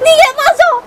Worms speechbanks
Runaway.wav